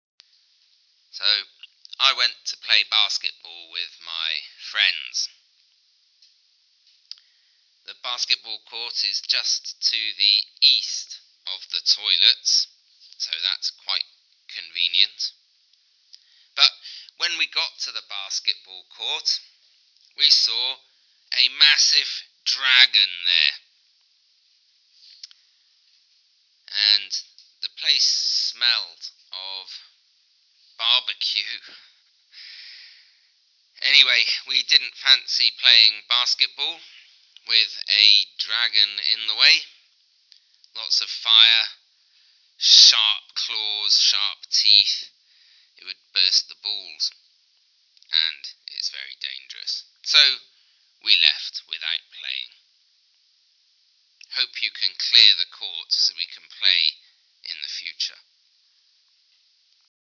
Listen to the basketball player
sport-centre-basketball-player.mp3